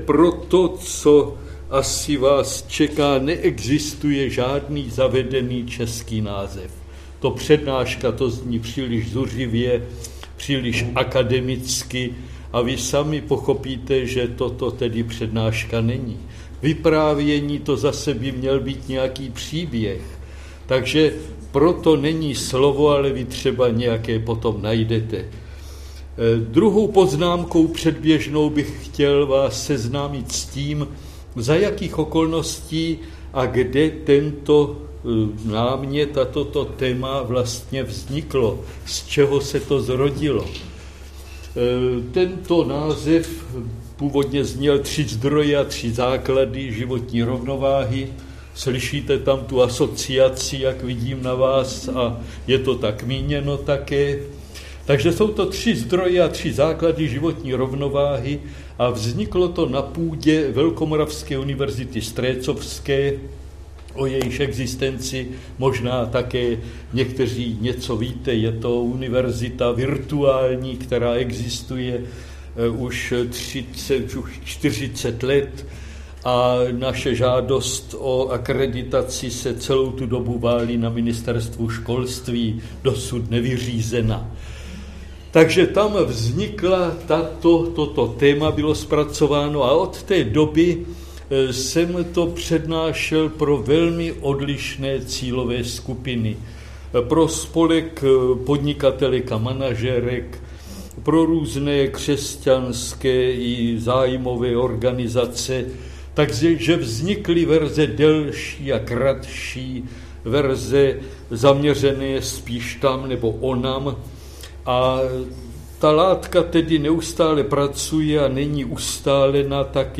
přednáška z projektu IZIDOR